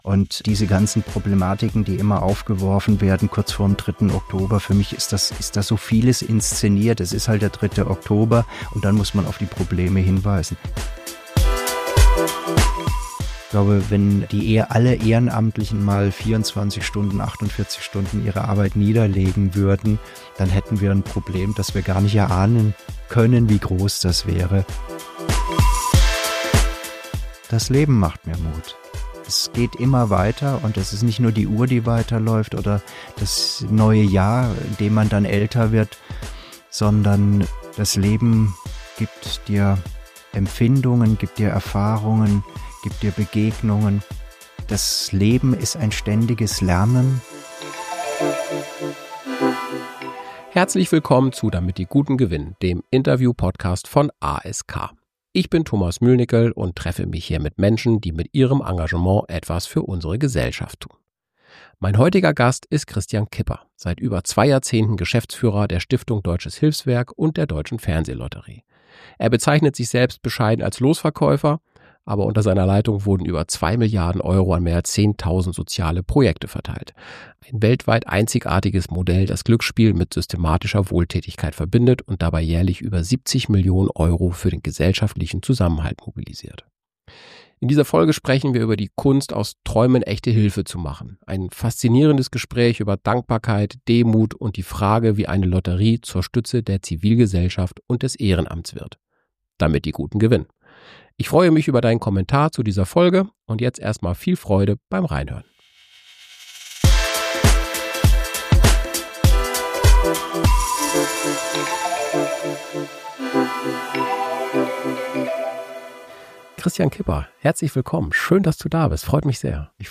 Ein Gespräch über Verantwortung, Wandel – und die Kraft der Gemeinschaft.